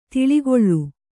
♪ tiḷigoḷḷu